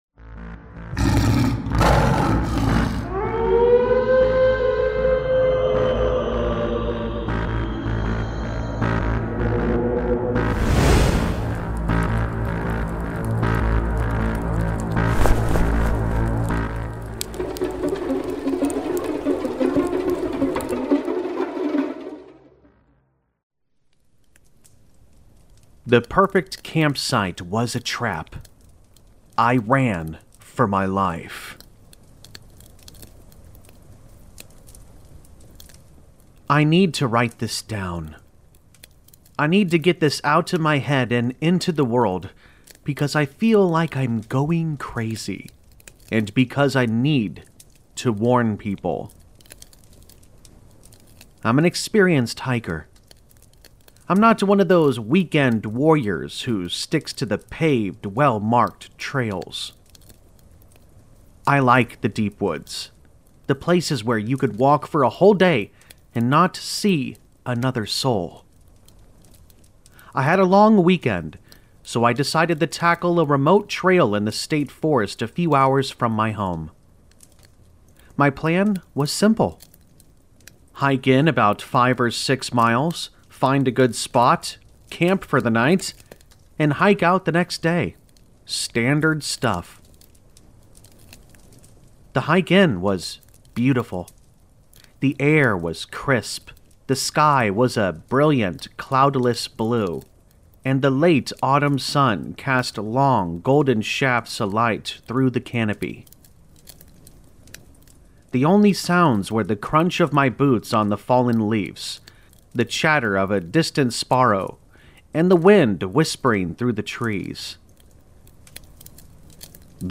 Packed with true scary story vibes, eerie atmosphere, and unsettling wilderness terror, this deep woods horror story will keep you on edge until the very last word.